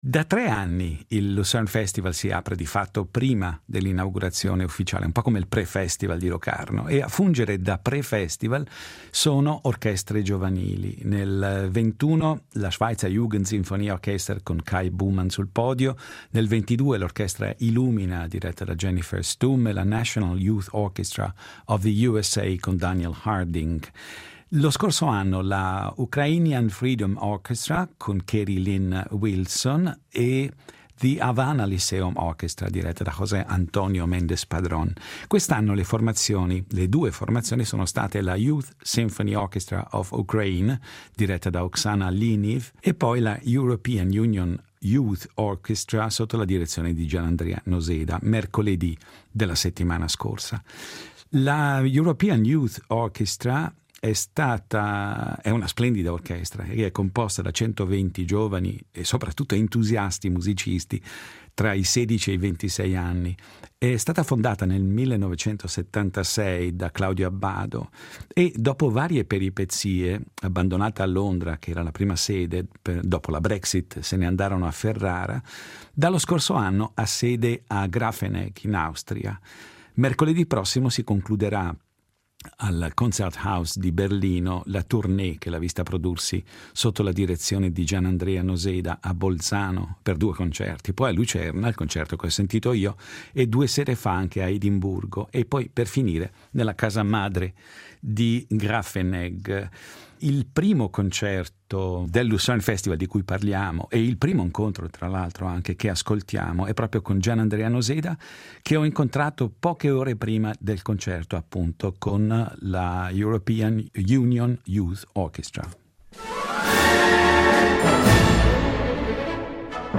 Incontro con Gianandrea Noseda